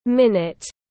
Phút tiếng anh gọi là minute, phiên âm tiếng anh đọc là /ˈmɪn.ɪt/
Minute /ˈmɪn.ɪt/